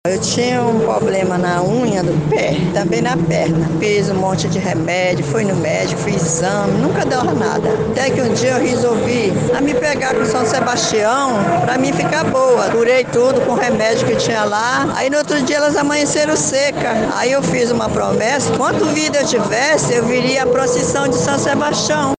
SONORA-2-PROCISSAO-SAO-SEBASTIAO-2.mp3